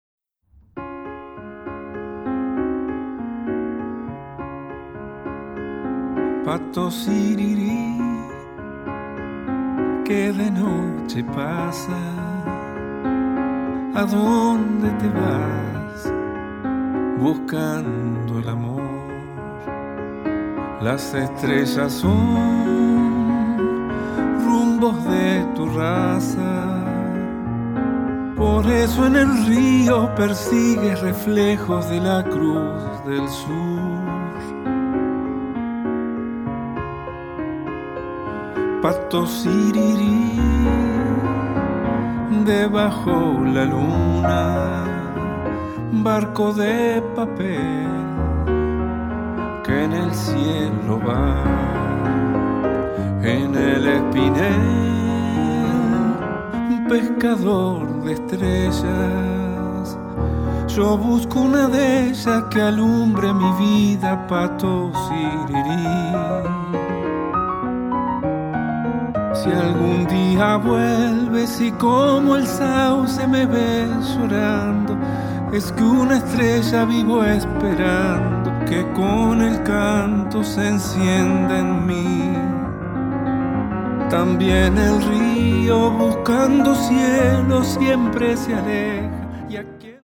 美しいピアノの弾き語り作品です！